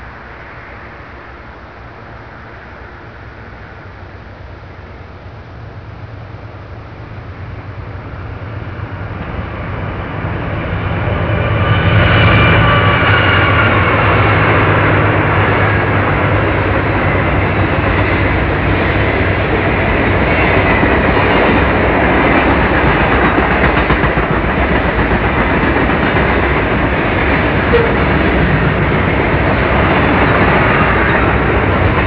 - Erie, Pennsylvania
CSX C40-8 leading an HLGX C30-7 in dynamic